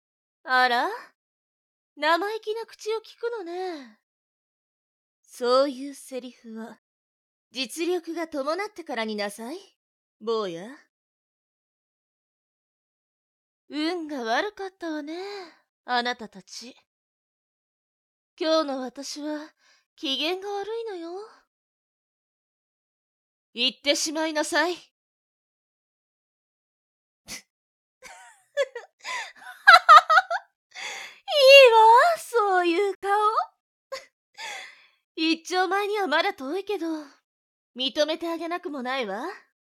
一丁前にはまだ遠いけど…認めてあげなくもないわ」 【 演じていただきました！ 】 名前：猫李（ビョウリ） 年齢：30歳 性別：女性 妖刀：九節鞭（属性：荊） すらりとしたスタイルの妖艶な猫の獣人女性。